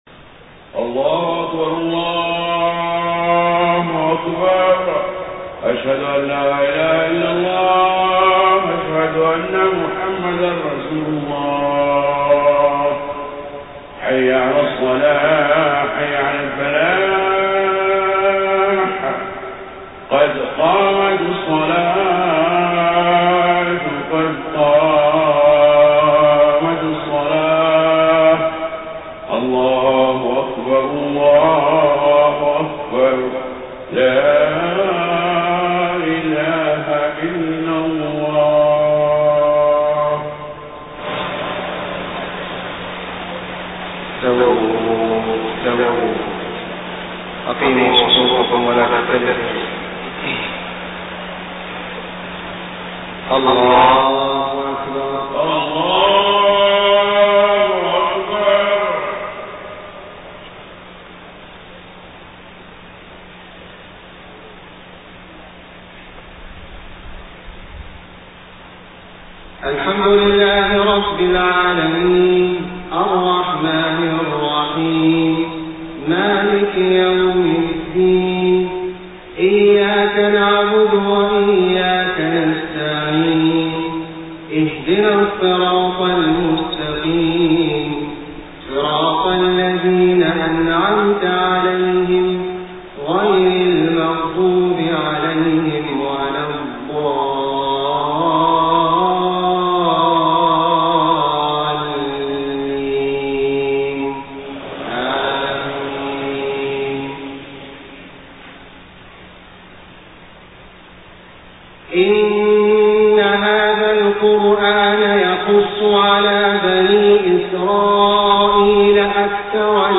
صلاة العشاء 28 صفر 1431هـ خواتيم سورة النمل 76-93 > 1431 🕋 > الفروض - تلاوات الحرمين